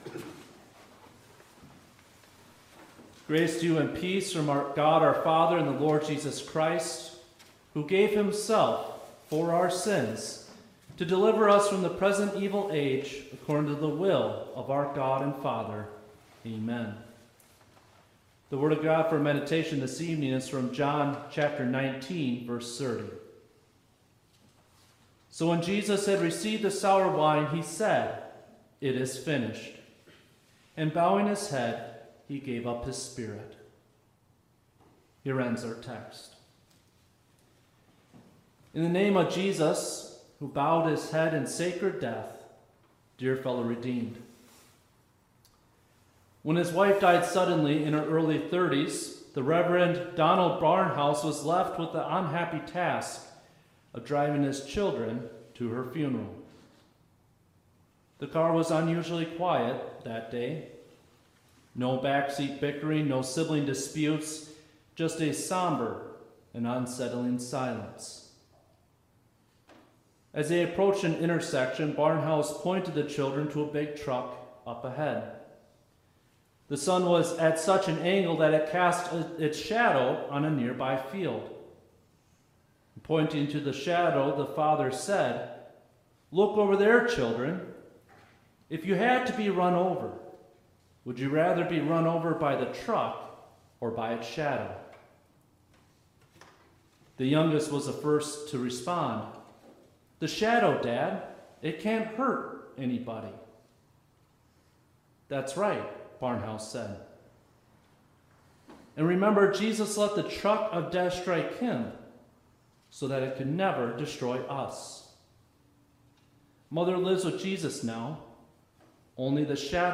6th-Midweek-Lenten-Service.mp3